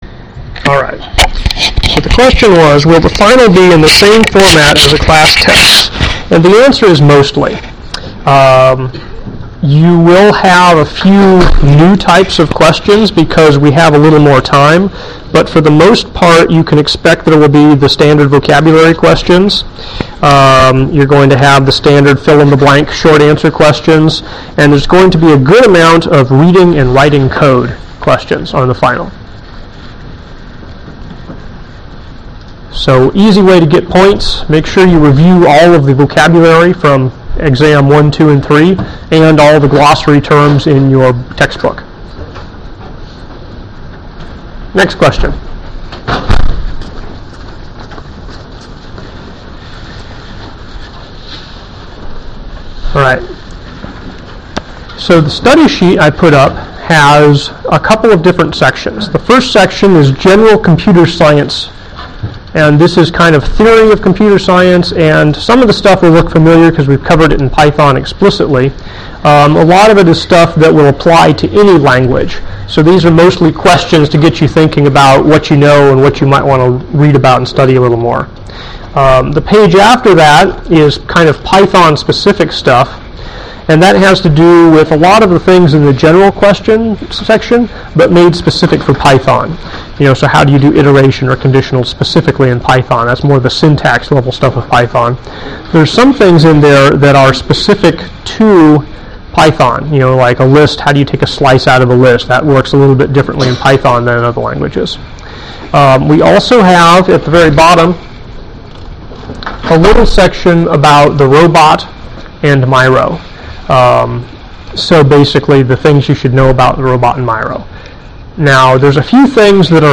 review_lec.mp3